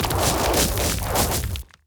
Rock Meteor Swarm 2.wav